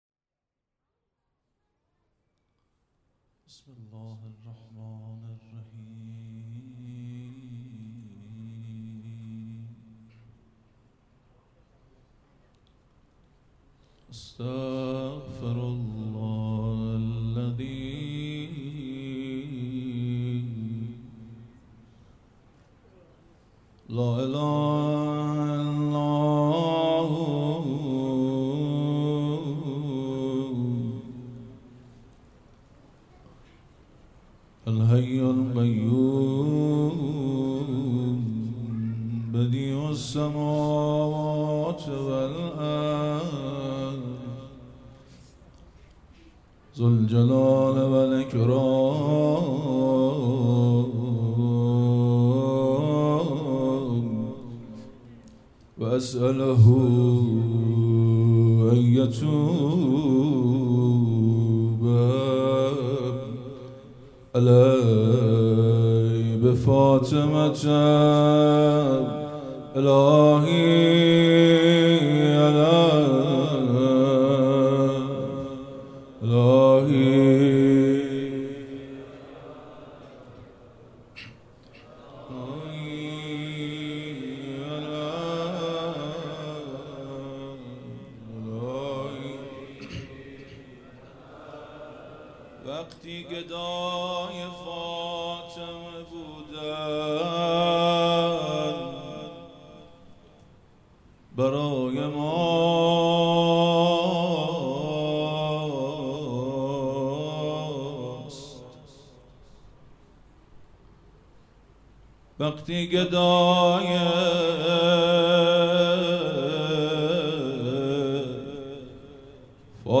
هیئت مکتب الزهرا(س)دارالعباده یزد - روضه | وقتی گدای فاطمه بودن برای ماست مداح